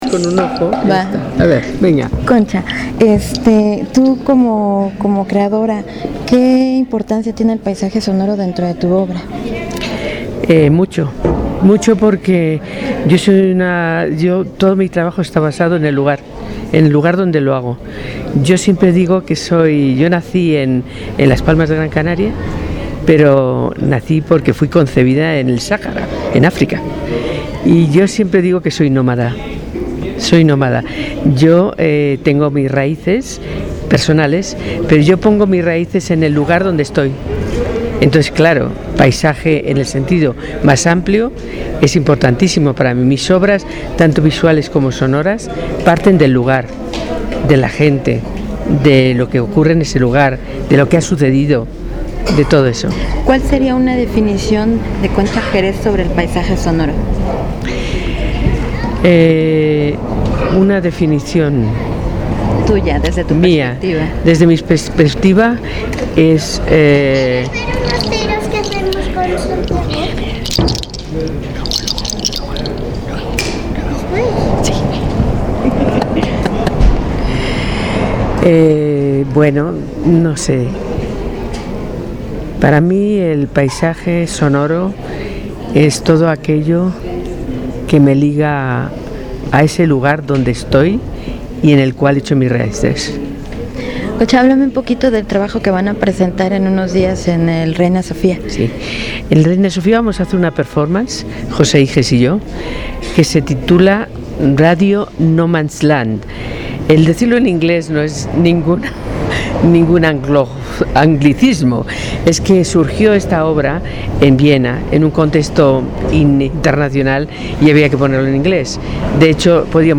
Entrevista
Lugar: Patio Central de la Fonoteca Nacional, Ciudad de Mexico.
Equipo: Sony IC Recorder ICD UX71 y micrófono binaural de construcción casera.